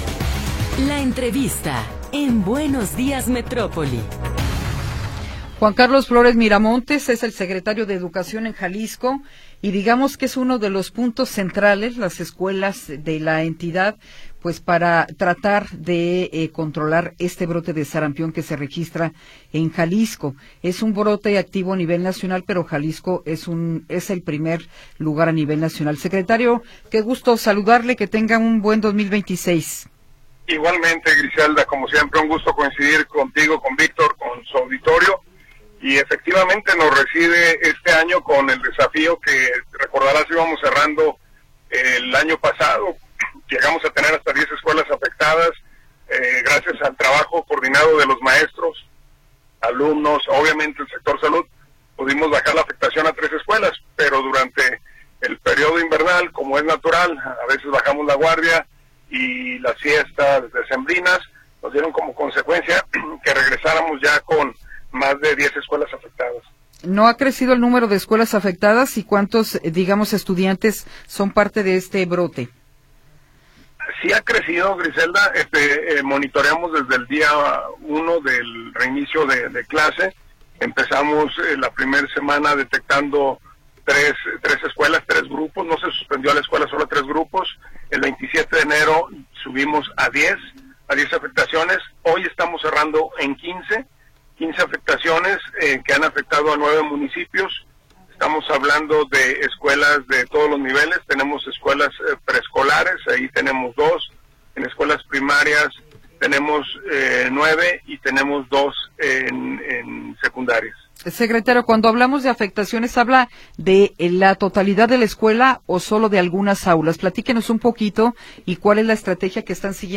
Entrevista con Juan Carlos Flores Miramontes
Juan Carlos Flores Miramontes, secretario de Educación en Jalisco, nos habla sobre la prevención del sarampión en las escuelas.
ENTREVISTA-1.m4a